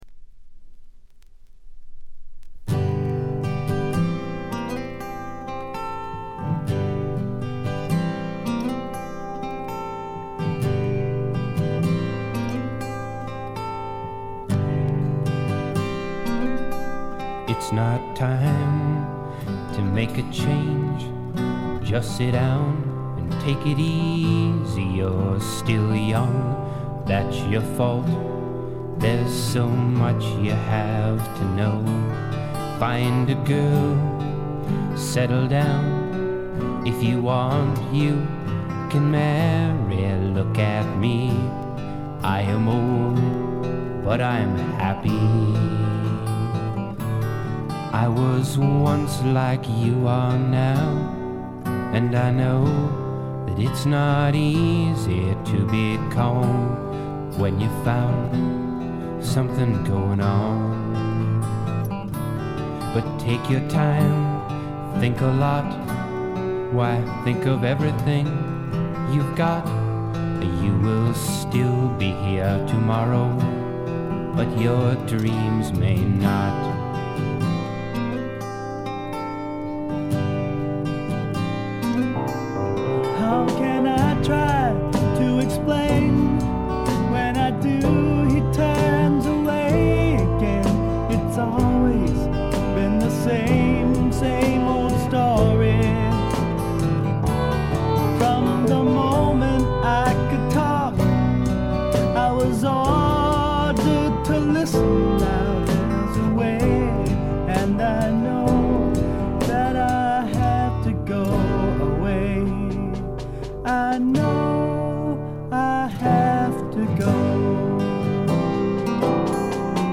静音部での軽微なチリプチ少々。
試聴曲は現品からの取り込み音源です。